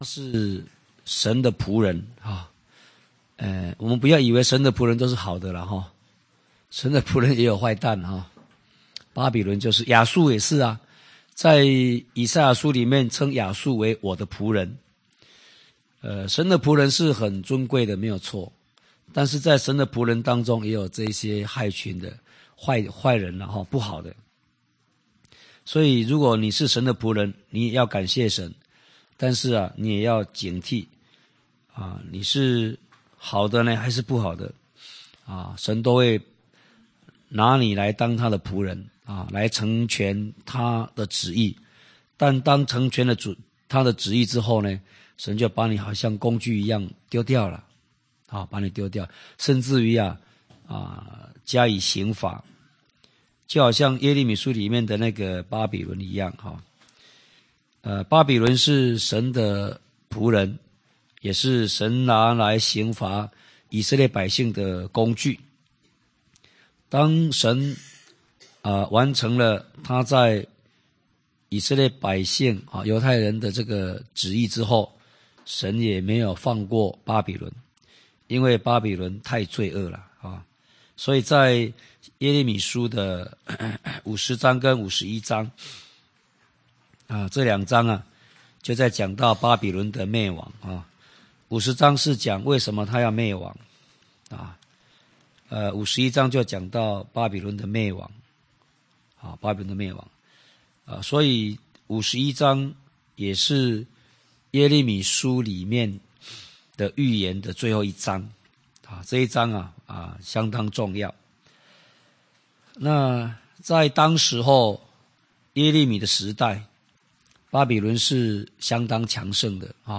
講習會
地點 台灣總會 日期 02/17/2011 檔案下載 列印本頁 分享好友 意見反應 Series more » • 耶利米書44-01：緒論(1